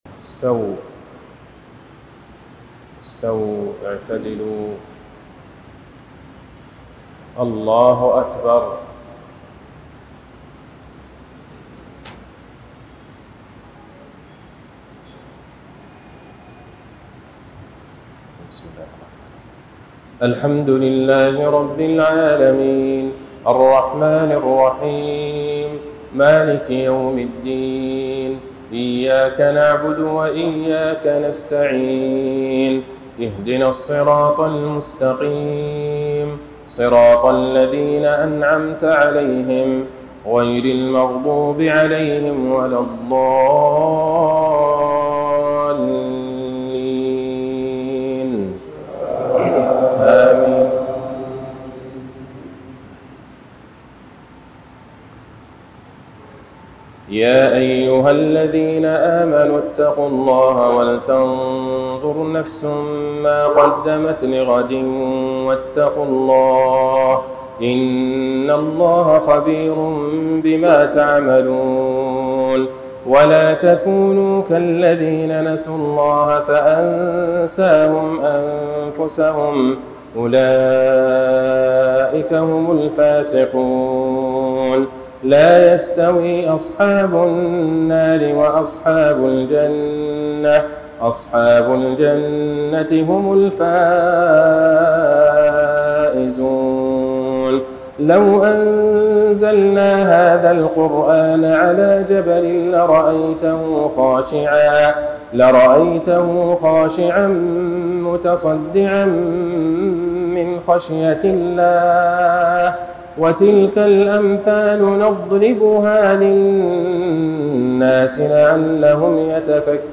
عشاء الجمعة ٢٢ شعبان ١٤٤٦هـ للشيخ عبدالله البعيجان في مسجد كتشانغاني في دار السلام عاصمة تنزانيا